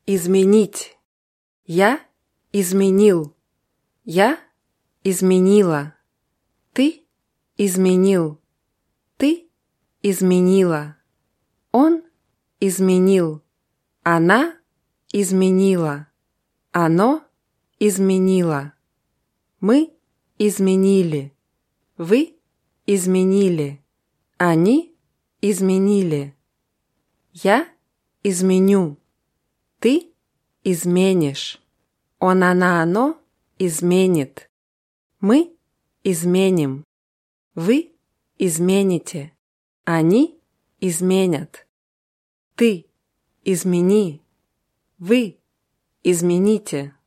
изменить [izmʲinʲítʲ]